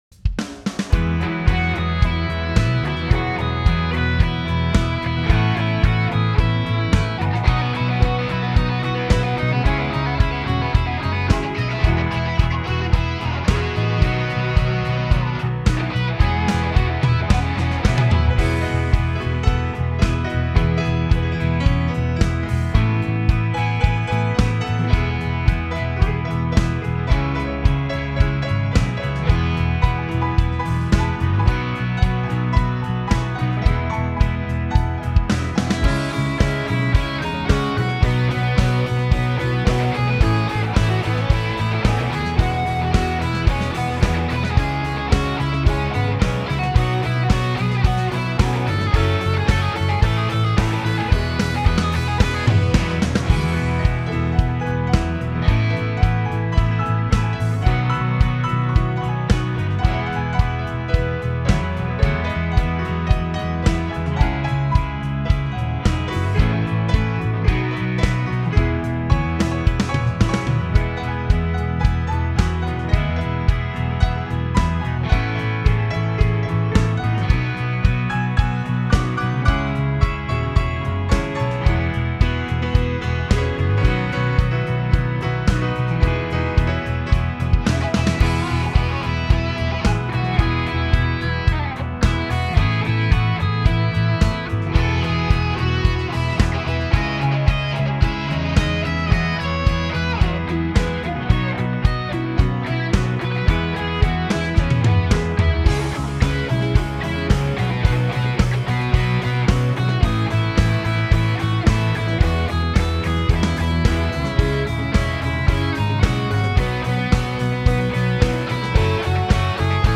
Home > Music > Rock > Bright > Laid Back > Restless